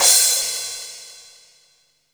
SC CRASH 1.wav